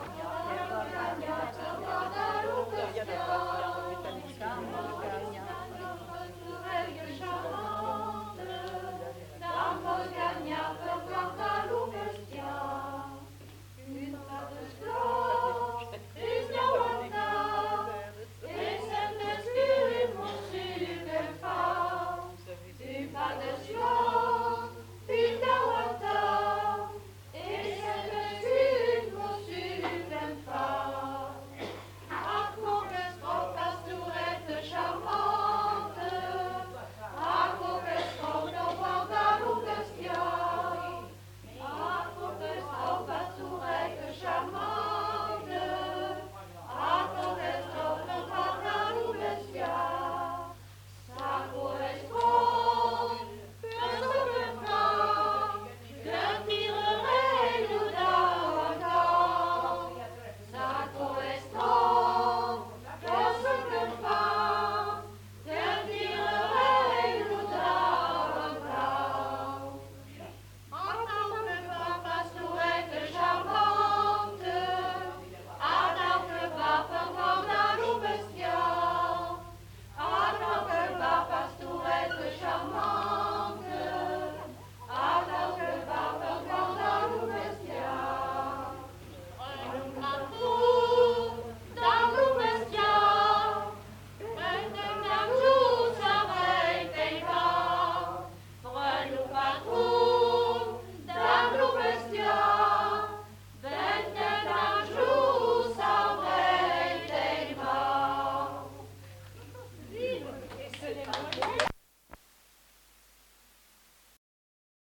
Aire culturelle : Grandes-Landes
Lieu : Salles
Genre : chant
Type de voix : voix de femme
Production du son : chanté
Notes consultables : Interprété par un ensemble vocal.